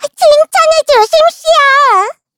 Taily-Vox_Victory_kr_b.wav